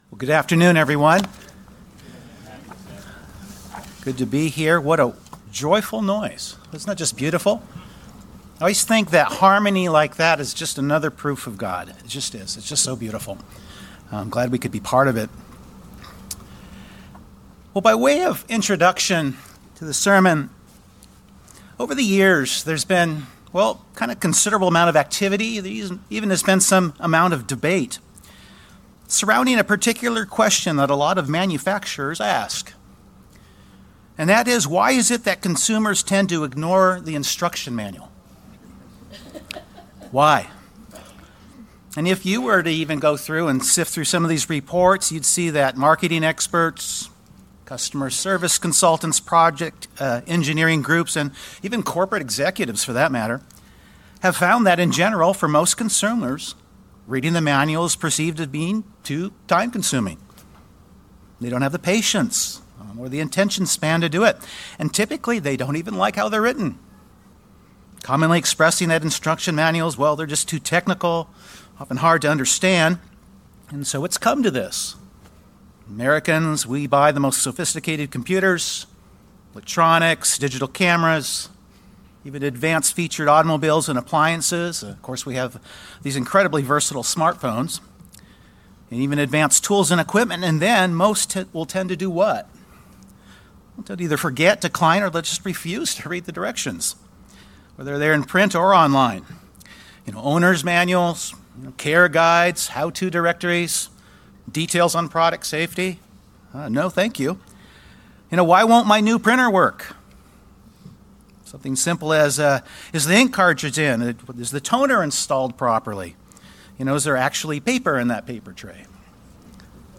In Luke chapter 18 we read of the parable of the persistent widow and the unjust judge and the question Jesus posed at the end of the parable about whether He will really find faith on the earth when He returns. The sermon will include a review of the parable and the answer to that question.